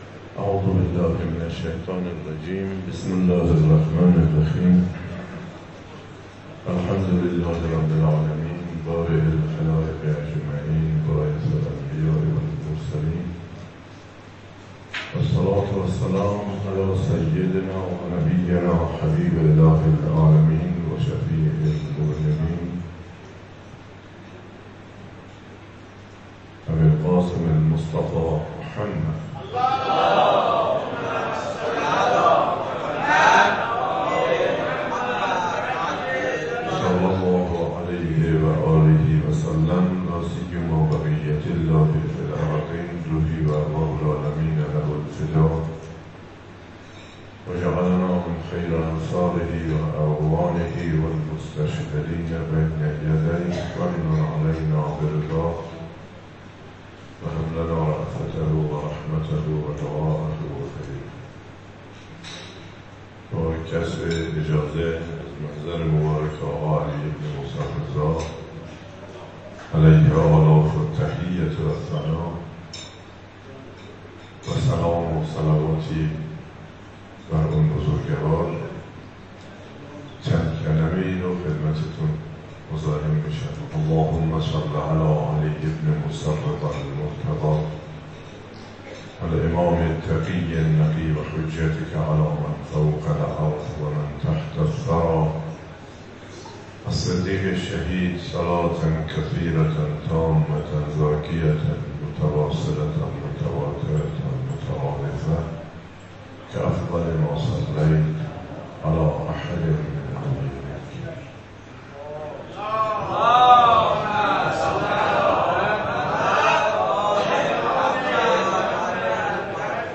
چهاردهمین همایش سراسری مسئولان جامعه قرآنی عصر